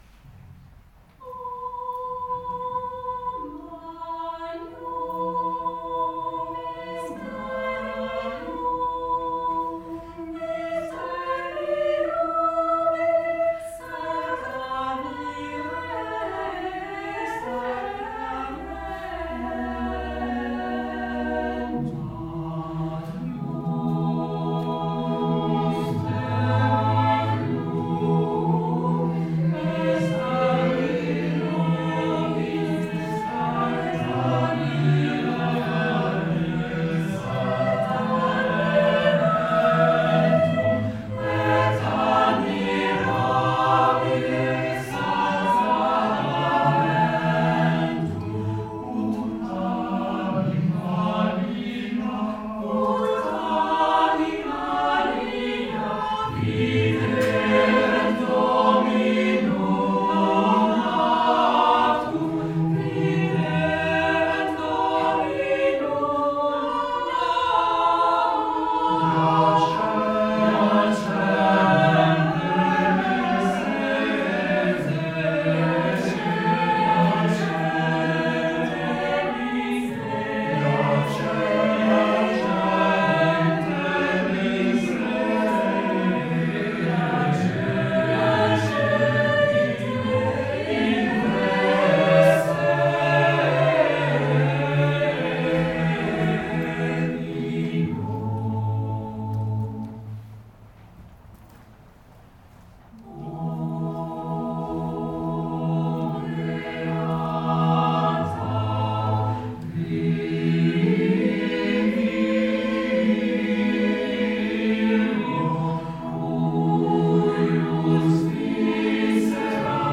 Quelques extraits de concerts | TOTUS
Voici quelques extraits de nos concerts (réalisés par des amateurs !) pour vous faire une idée…